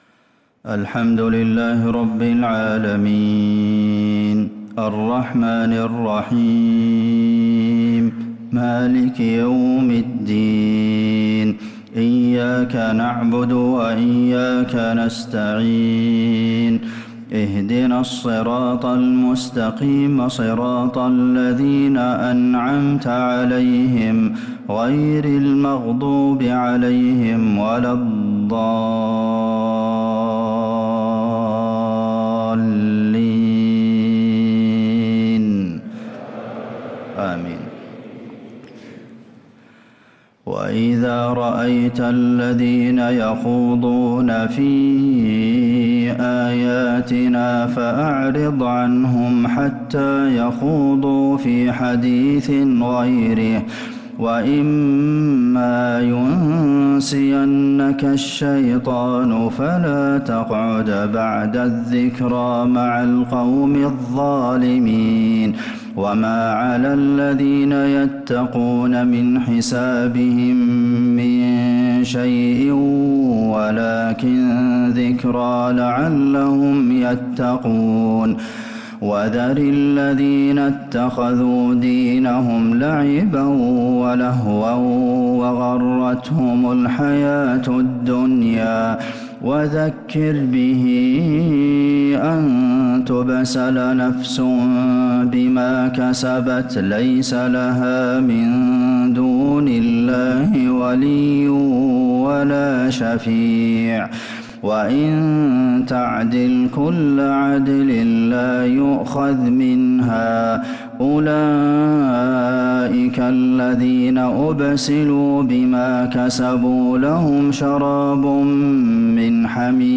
عشاء الثلاثاء 5-8-1446هـ | من سورة الأنعام 68-73 | Isha prayer from Surah Al-An’aam 4-2-2025 > 1446 🕌 > الفروض - تلاوات الحرمين